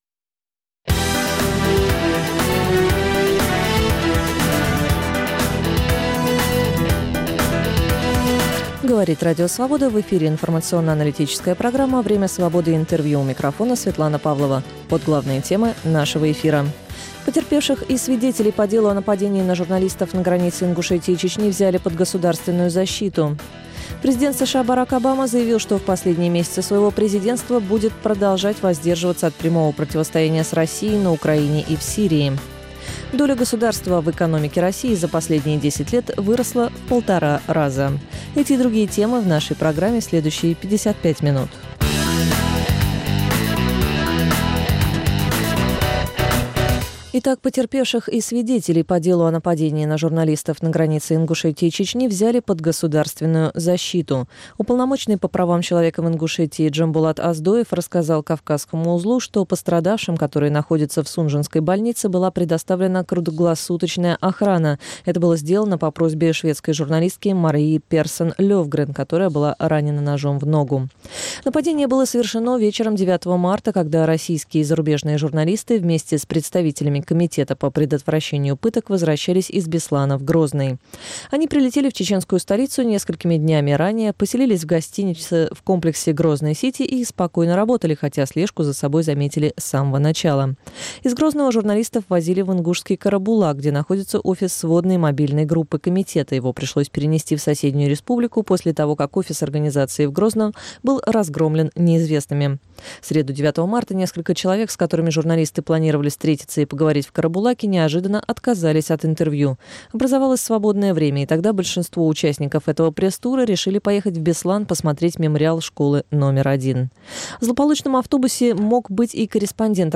Время Свободы - Интервью